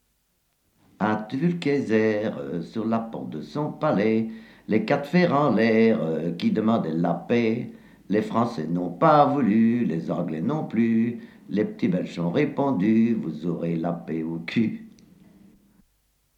Type : chanson de lutte sociale ou de résistance | Date : 1972/73